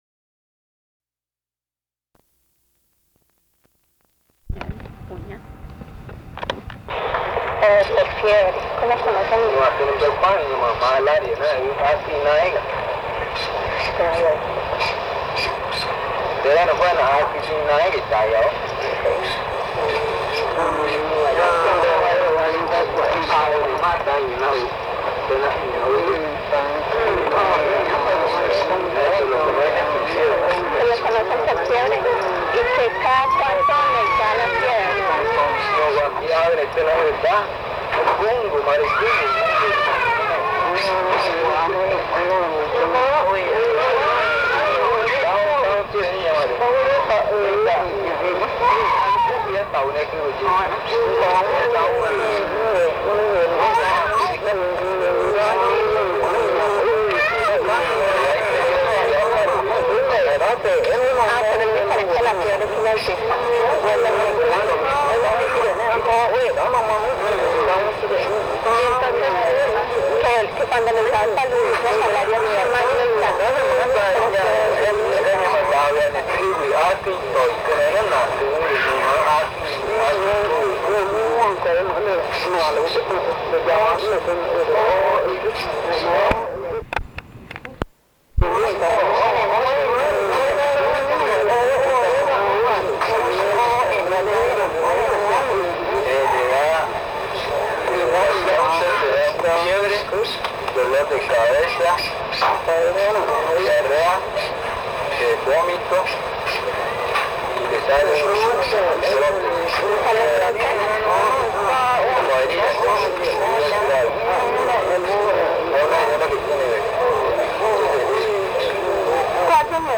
Encuesta léxica y gramatical en Pupuña (Río Cotuhé) - casete 4
Este casete es el cuarto de una serie de cuatro casetes grabados en Pupuña.
El audio contiene los lados A y B.